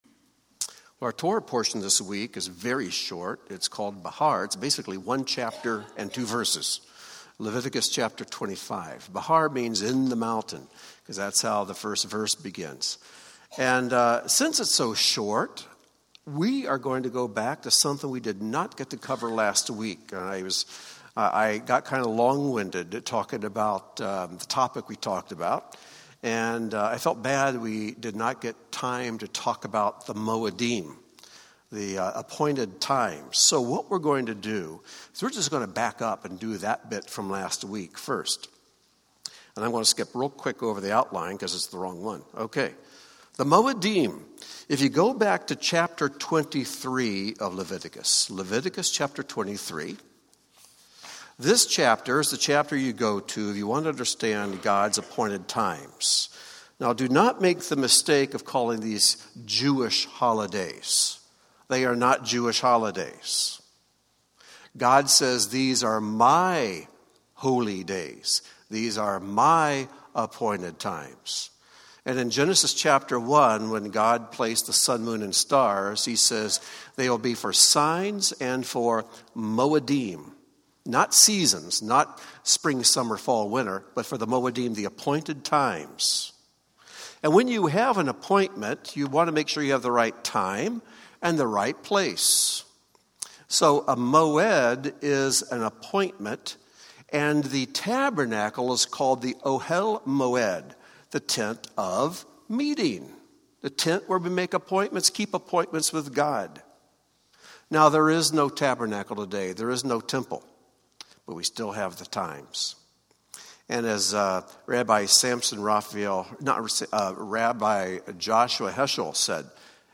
We begin this week’s teaching by first stepping back to Torah portion Emor to address the important topic of the Lord’s appointed times, the mo’edim, with special focus on the seemingly oddly placed verse that separates the spring and fall mo’edim.